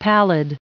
added pronounciation and merriam webster audio
907_pallid.ogg